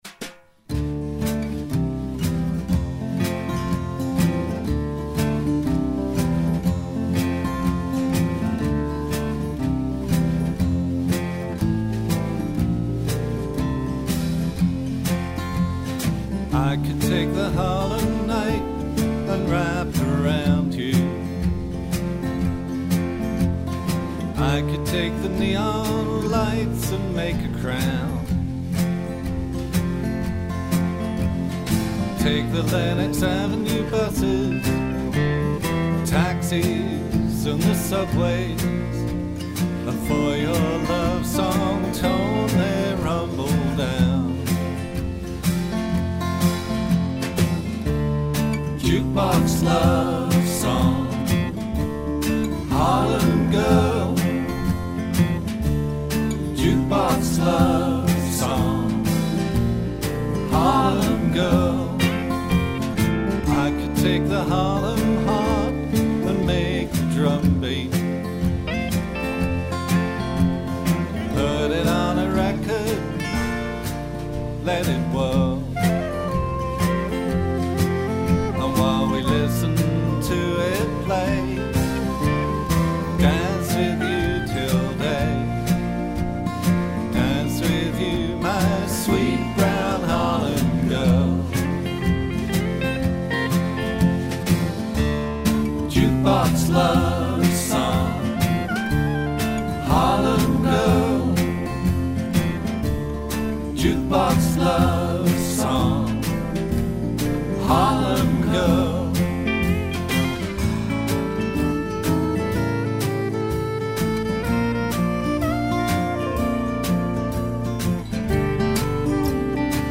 vocals, acoustic guitar
banjo
electric guitar
bass
alto & soprano sax
drums